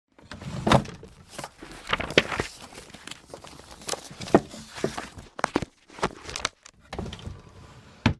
bookcase_0.ogg